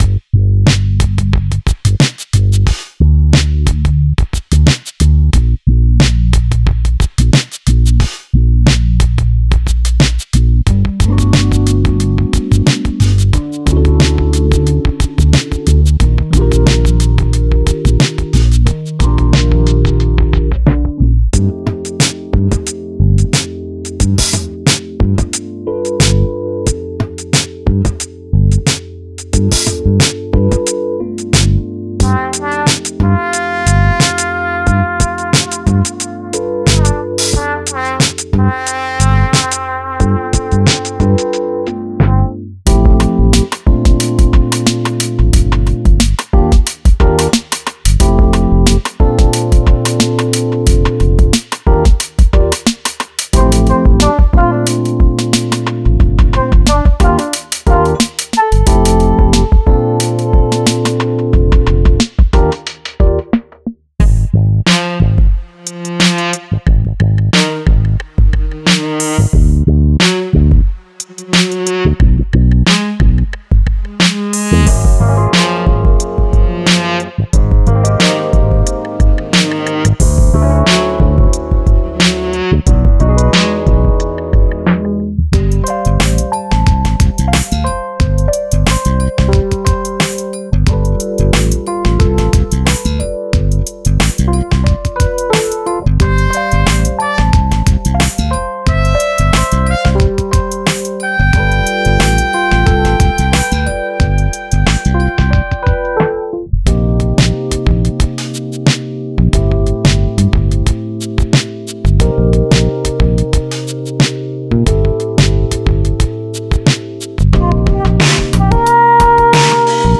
Construction Kits